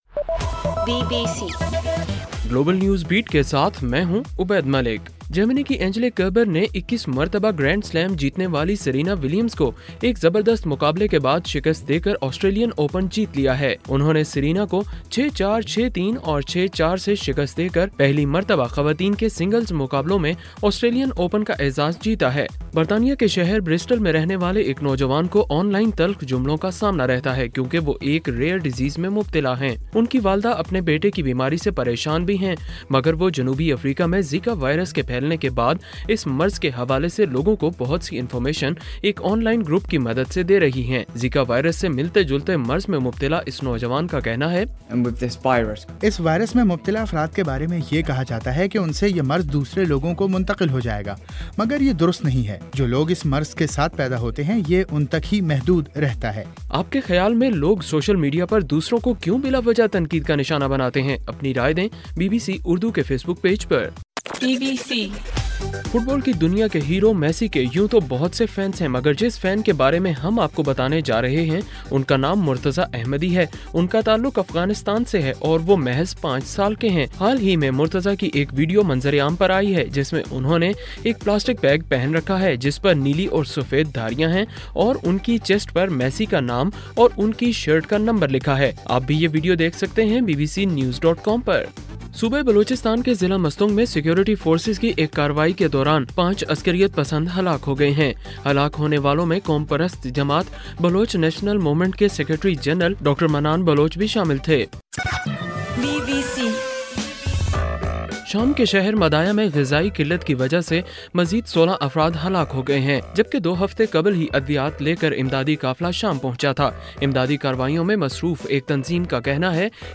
جنوری 30: رات 9 بجے کا گلوبل نیوز بیٹ بُلیٹن